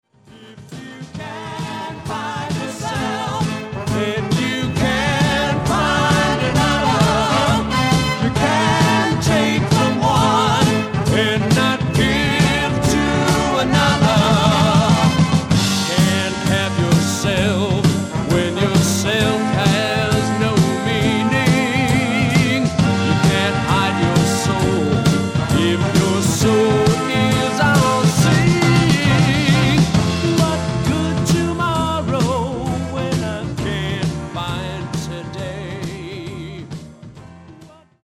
SOFT ROCK / PSYCHEDERIC POP